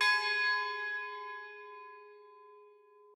bell1_5.ogg